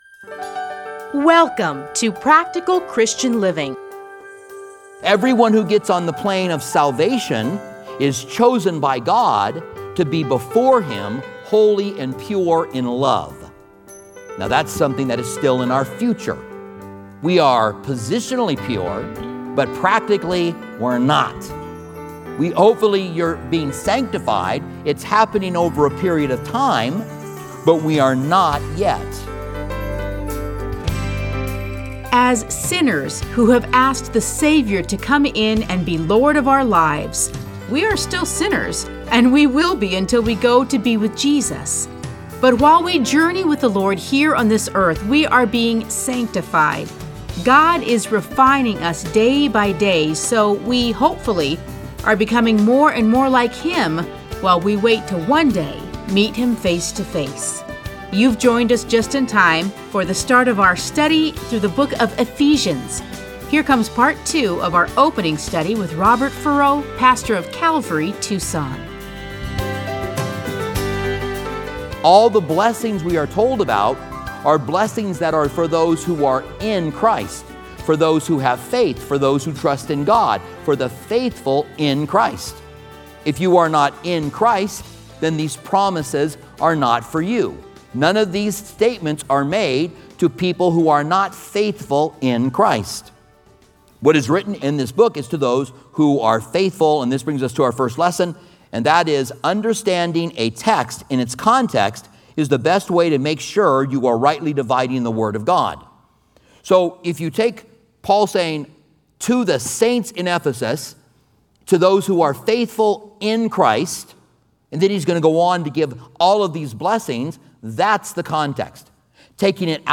Listen to a teaching from Ephesians 1:1-10.